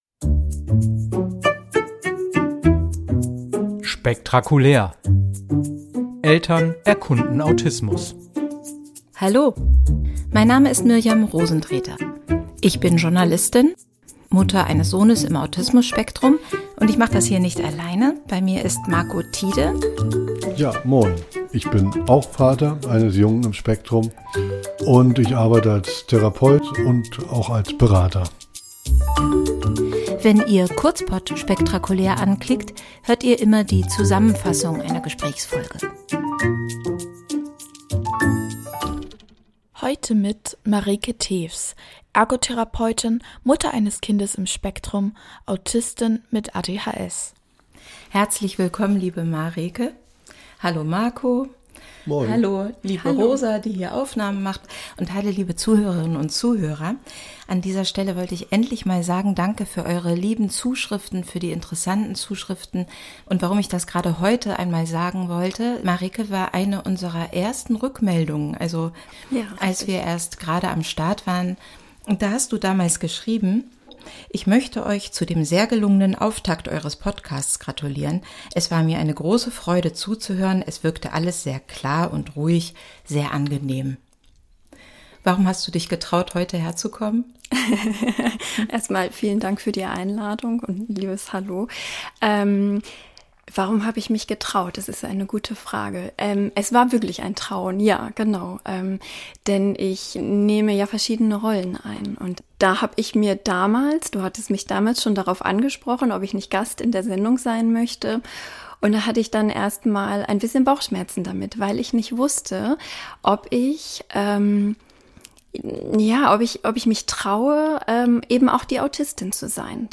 Für den Podcast Spektrakulär reden die Journalistin und der Autismus-Therapeut mit Autistinnen und Autisten. Angehörige und Fachleute erzählen, was sie gemeinsam mit Menschen aus dem Spektrum über Autismus herausfinden.